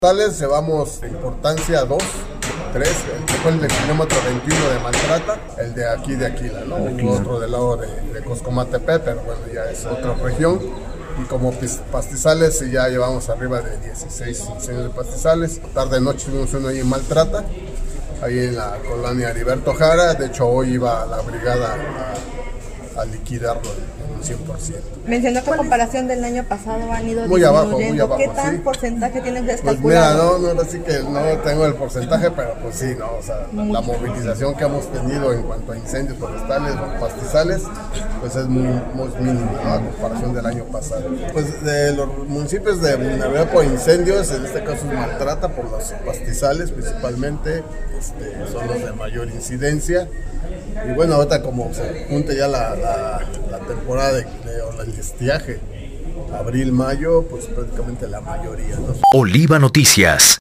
En entrevista, detalló que el más crítico de ellos fue el ocurrido en el municipio de Aquila, pues dadas las condiciones climáticas adversas, como la surada y las altas temperaturas fue difícil de exterminar.